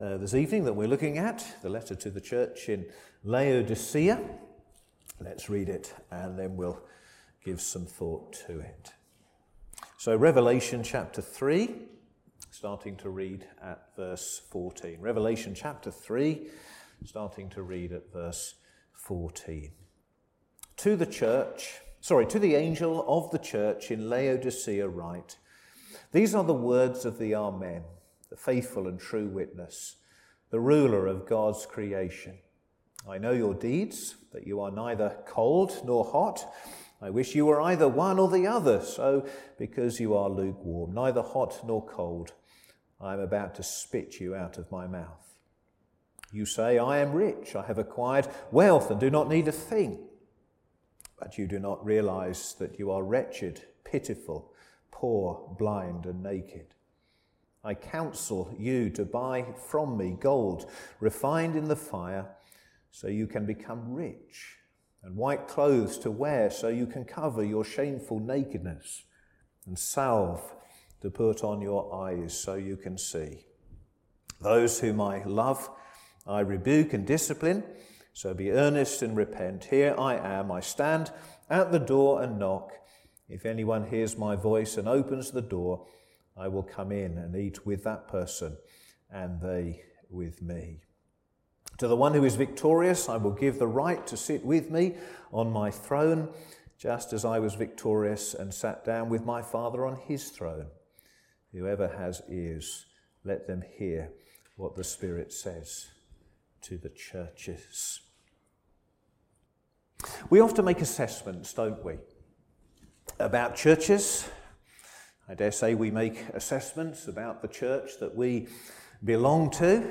Sermons
Service Evening